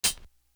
Icewater Hat.wav